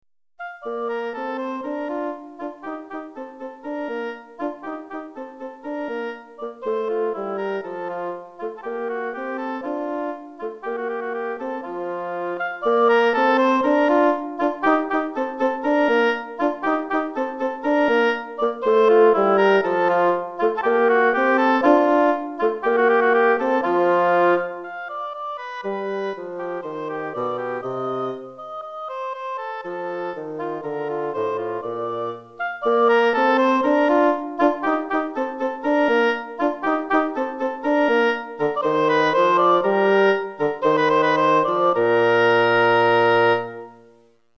Hautbois et Basson